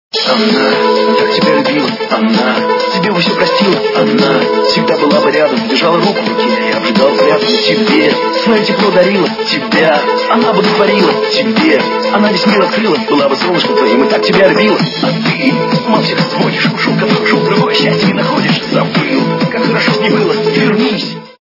- русская эстрада
При заказе вы получаете реалтон без искажений.